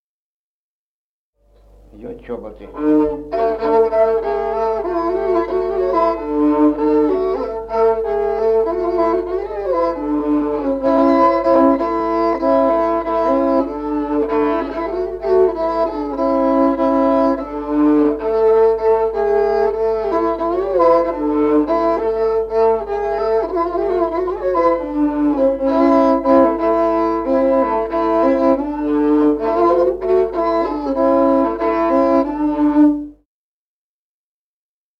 Музыкальный фольклор села Мишковка «Чоботы», партия 2-й скрипки.